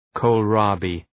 Προφορά
{kəʋl’rɑ:bı}